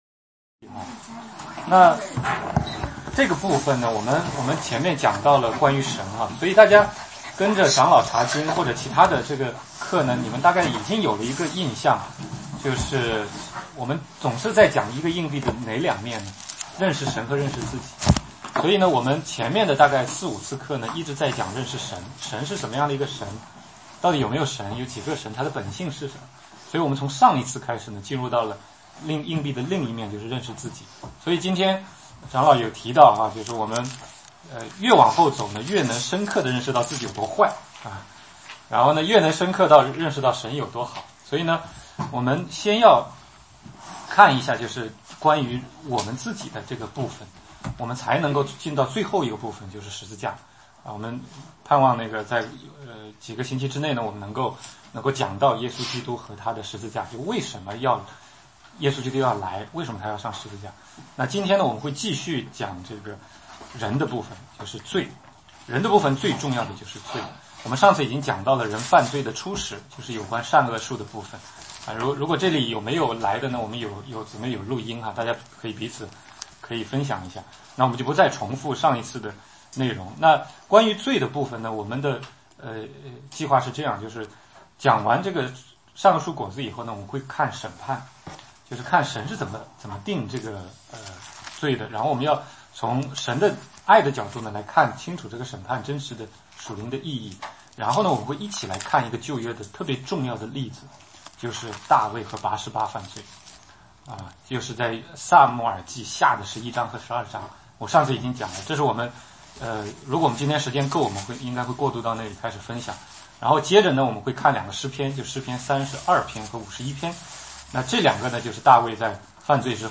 16街讲道录音 - 罪与救赎